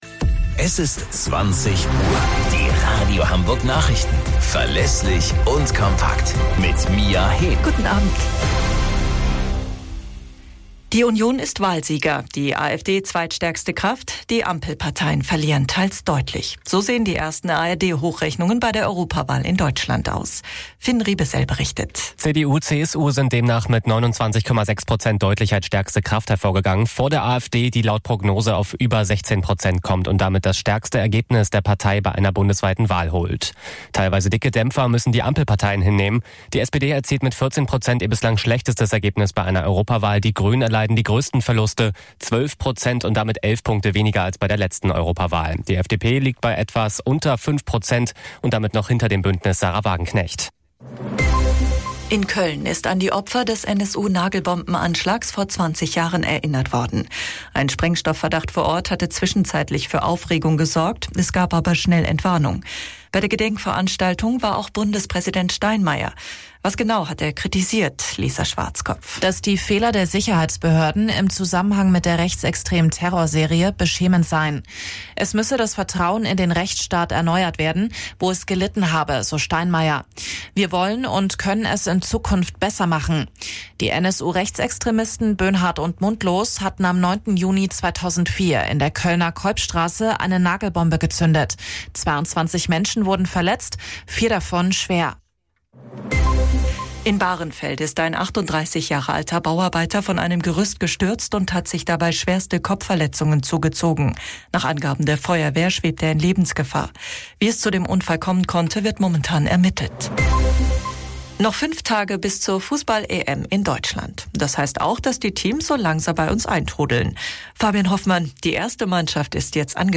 Radio Hamburg Nachrichten vom 10.06.2024 um 03 Uhr - 10.06.2024